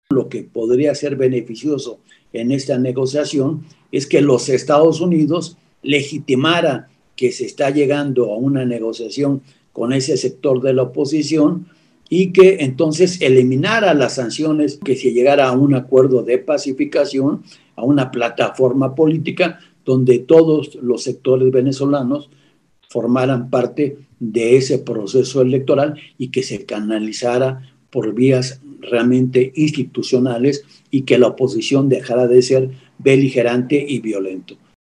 En conferencia de medios a distancia